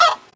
better_fauna_chicken_death.ogg